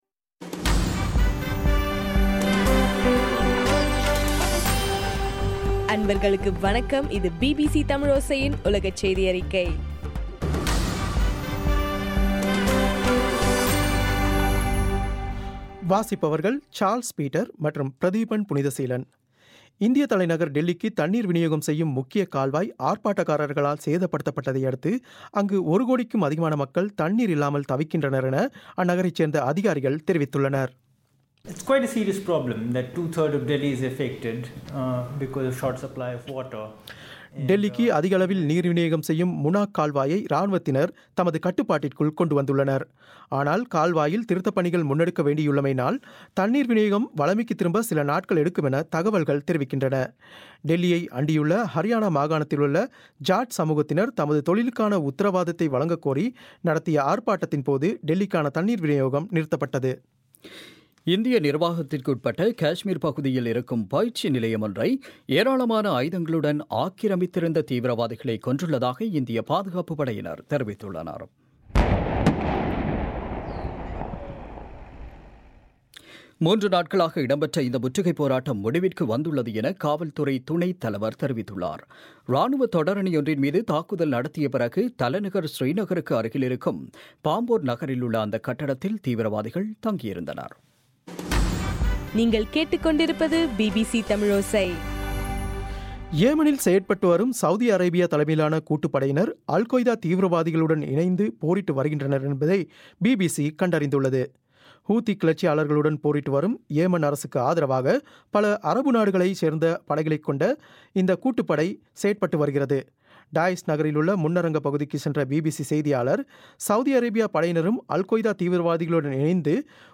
பிபிசி தமிழோசை உலகச் செய்தியறிக்கை- பிப்ரவரி 22